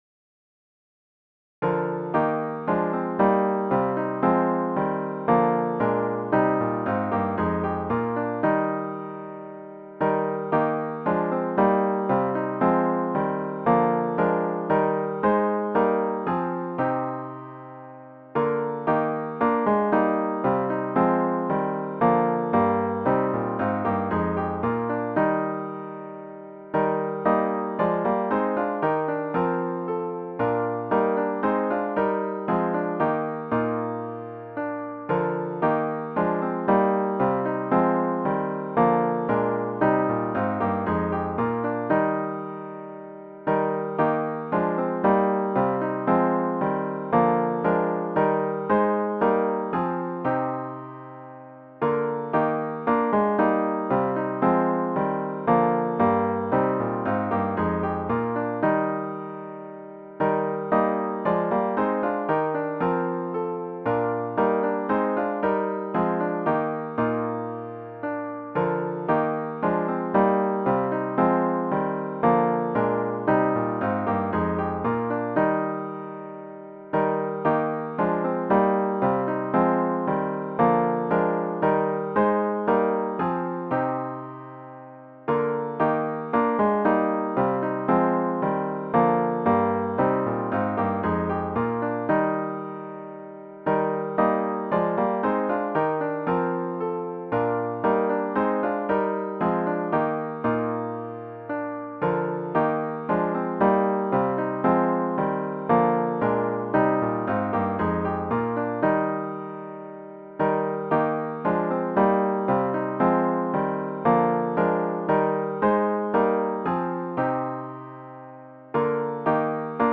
Sunday July 16, 2023 Worship Service
*HYMN “For All the Faithful Women” GtG 324    For All the Faithful Women, Text by Herman G. Stuempfle, Jr. Copyright 1993, GIA Publications, Inc., All rights reserved.